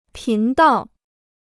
频道 (pín dào) พจนานุกรมจีนฟรี